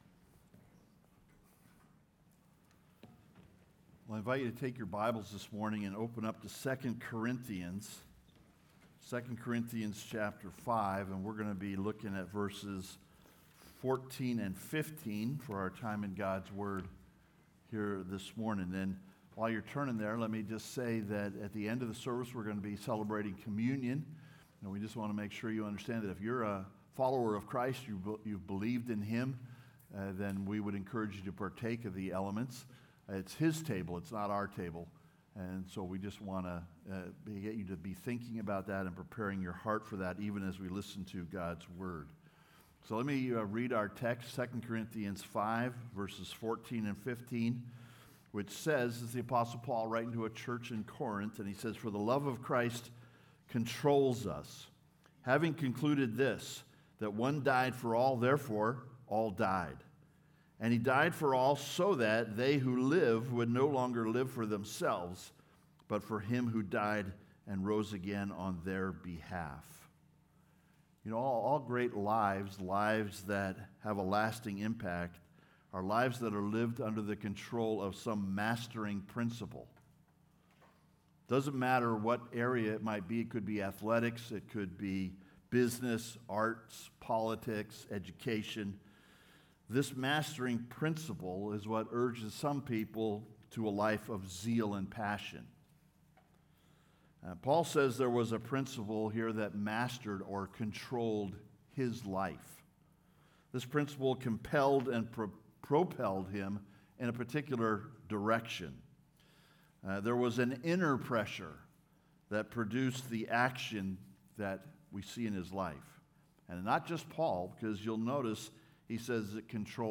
Controlled By Christ's Love (Sermon) - Compass Bible Church Long Beach